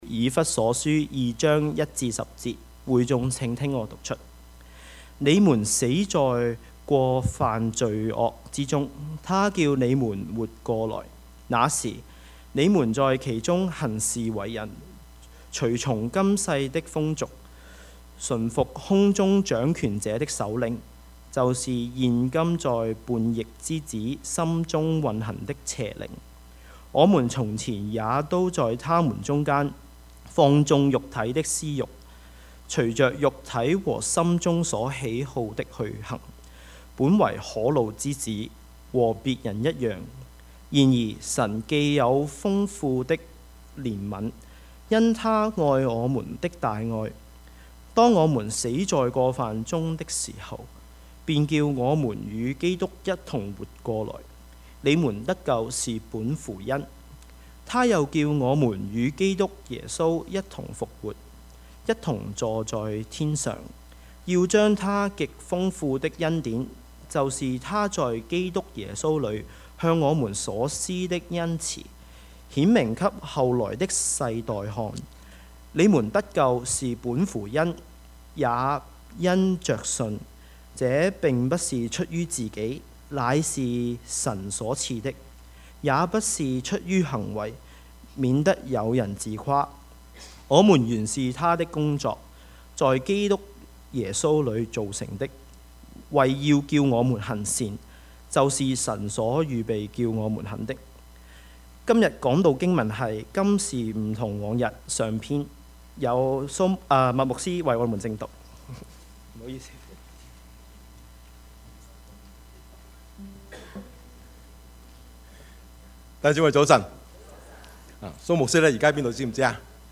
Service Type: 主日崇拜
Topics: 主日證道 « 照明心眼的禱告 今時唔同往日（下） »